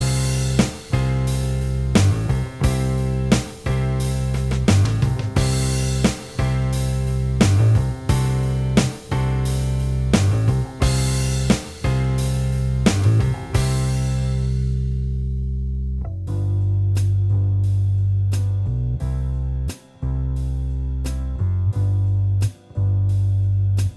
Minus Guitars Soft Rock 4:13 Buy £1.50